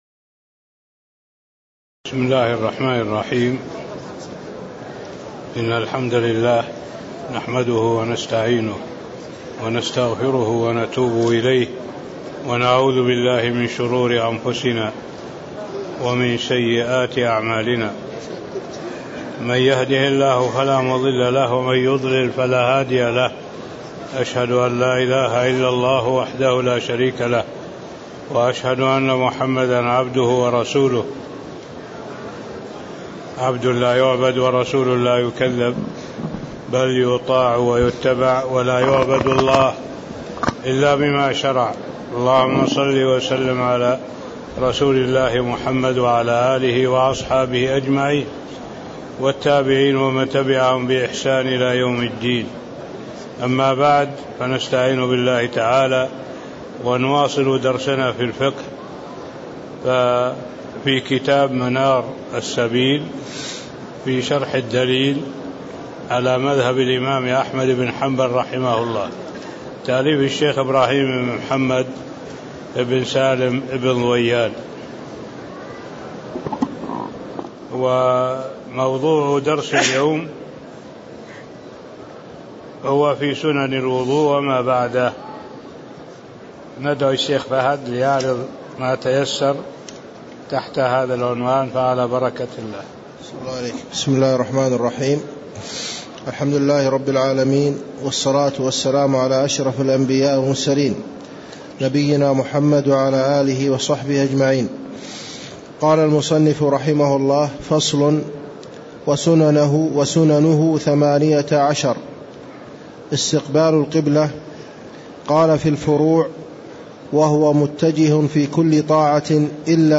تاريخ النشر ١٥ جمادى الآخرة ١٤٣٦ هـ المكان: المسجد النبوي الشيخ